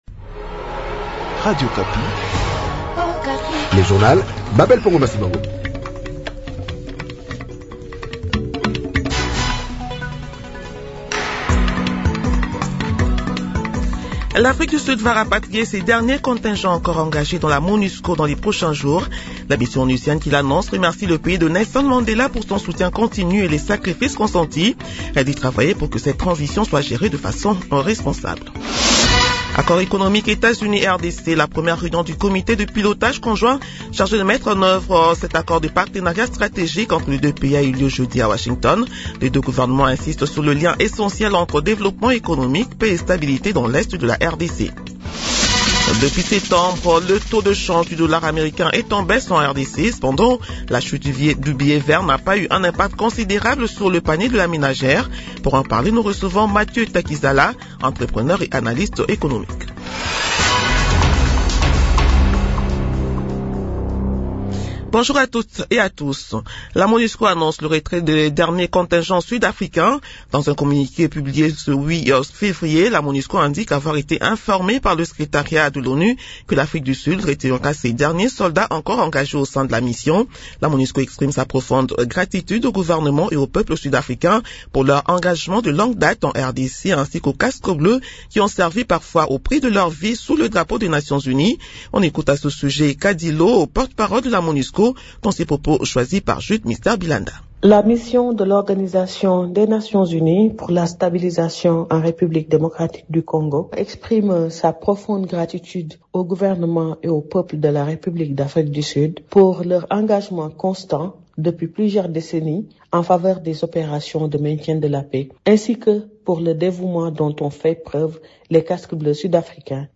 Journal de 7 heures de ce lundi 9 février 2026